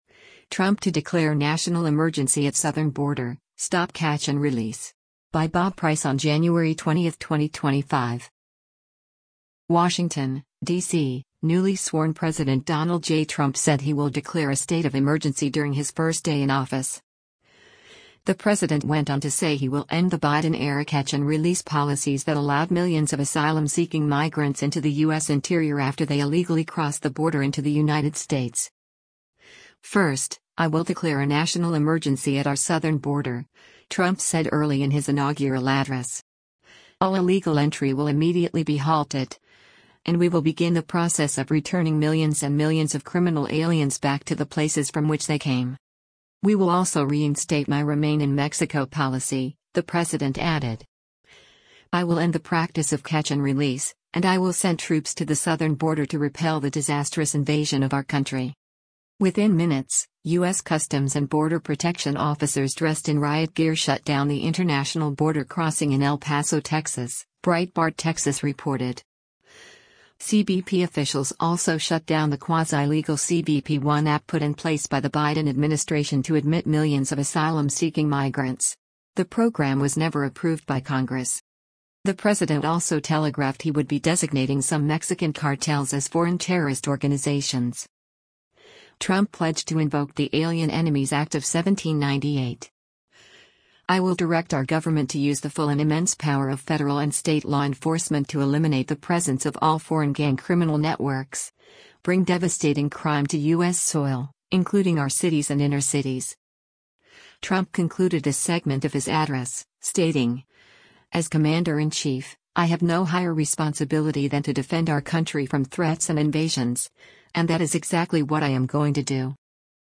“First, I will declare a national emergency at our southern border,” Trump said early in his inaugural address.